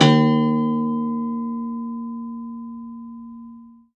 53p-pno03-D0.wav